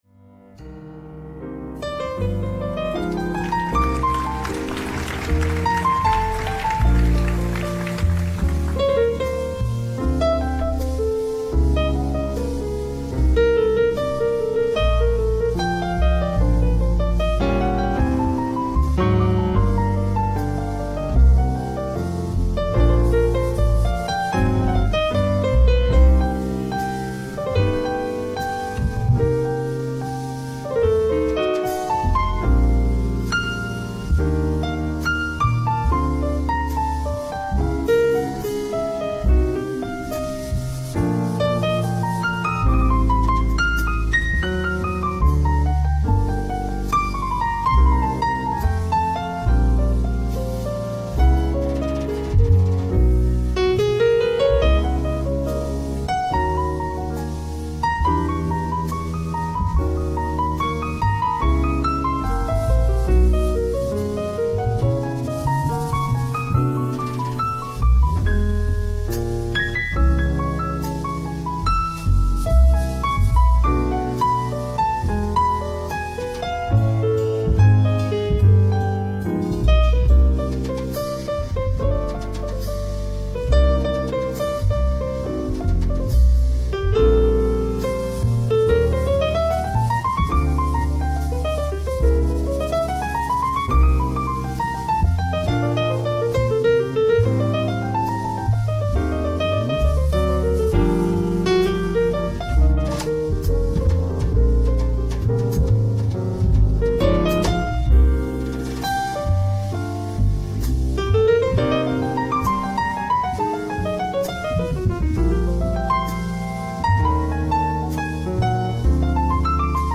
※試聴用に実際より音質を落としています。